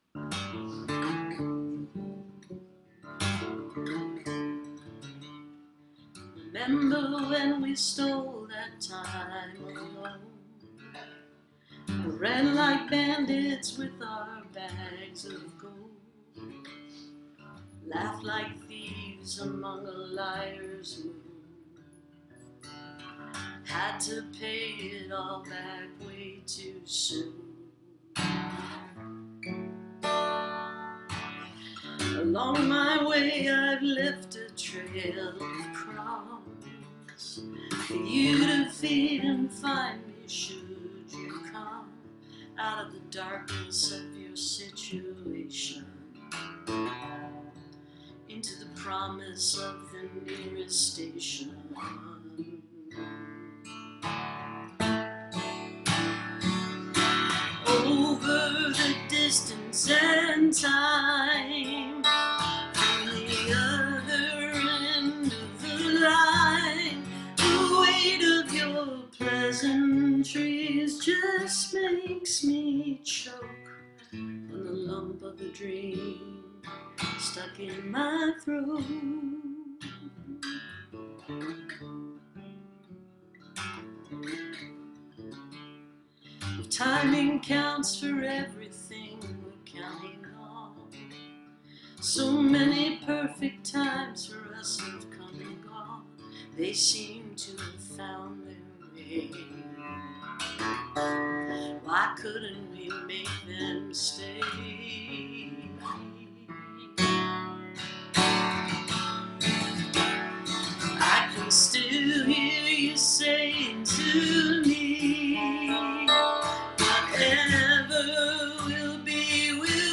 (captured from the live video stream)